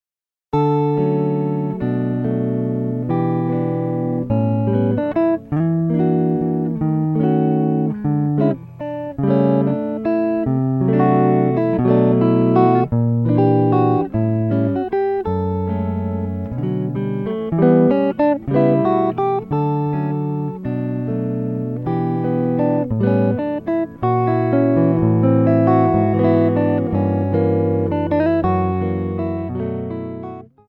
solo guitar arrangements